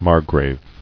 [mar·grave]